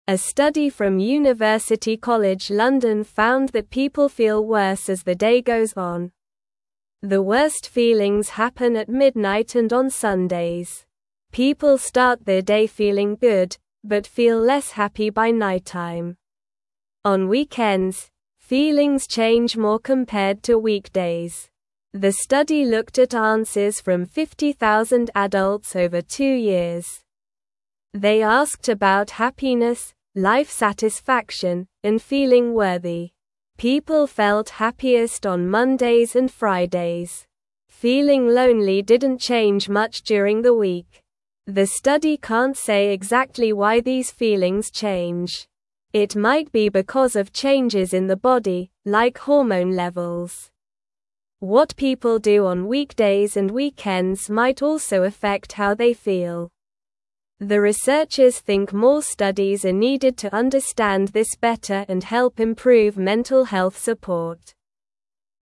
Slow
English-Newsroom-Lower-Intermediate-SLOW-Reading-Why-People-Feel-Happier-at-the-Start-of-Days.mp3